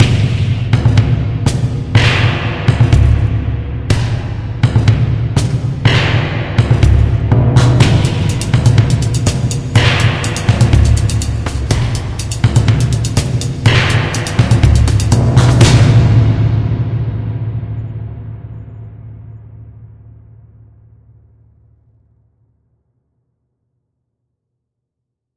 描述：Particular sound of Stop Lights in the Streets of Hon Kong
标签： soundscape Lights Kong Stop fieldrecording Hong City
声道立体声